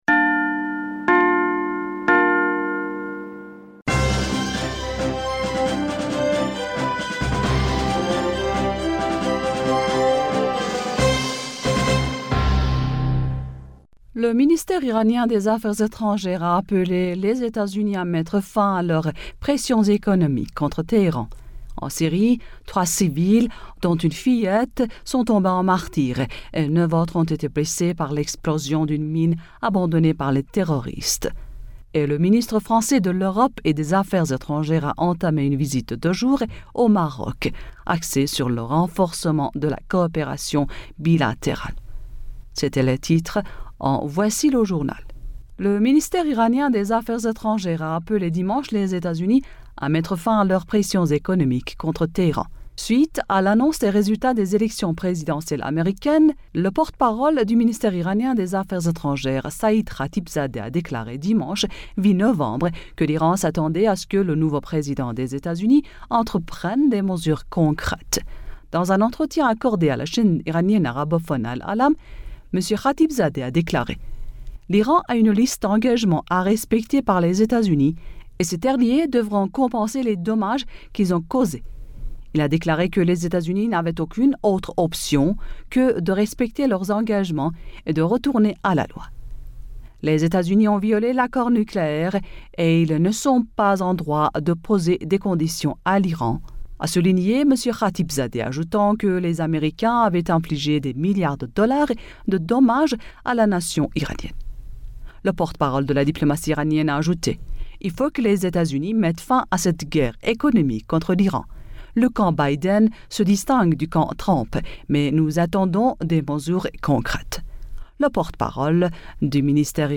bulletin d'information